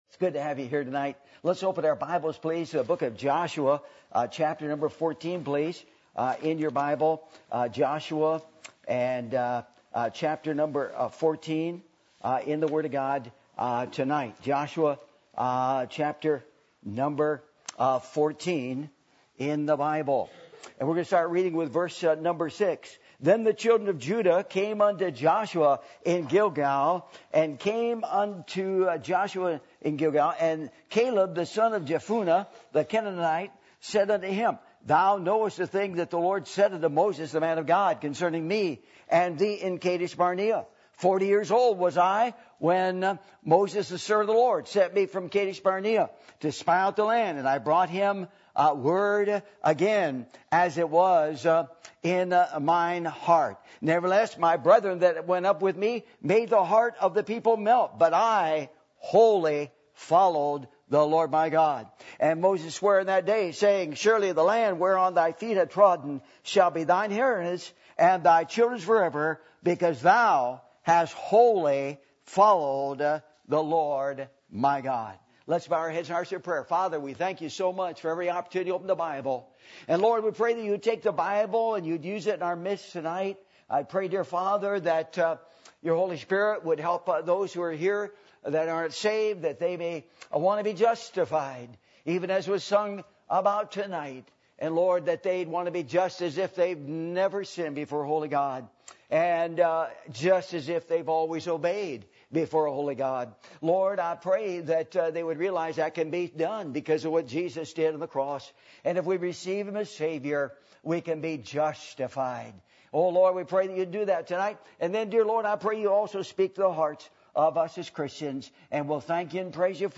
Service Type: Revival Meetings